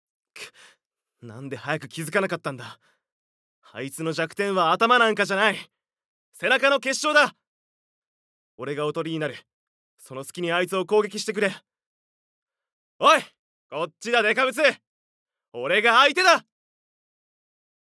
ボイスサンプル
戦う主人公